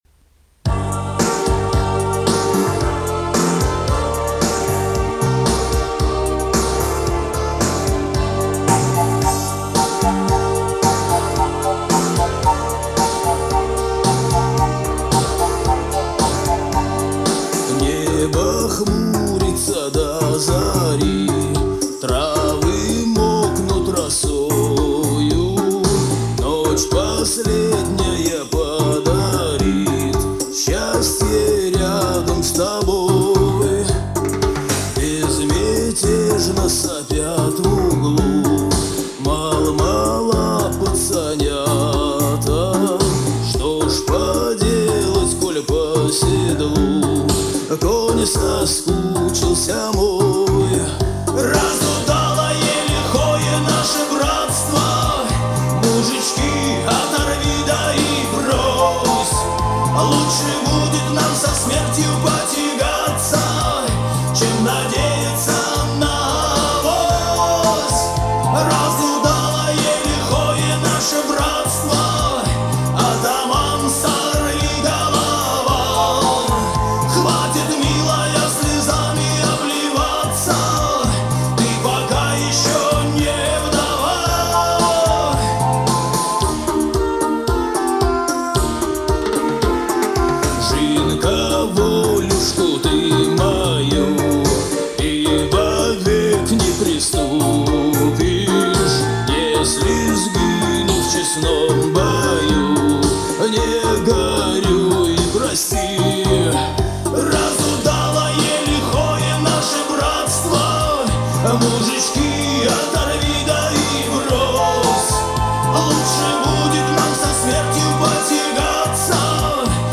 Явно не Расторгуев, а вот кто???
Запись хорошая!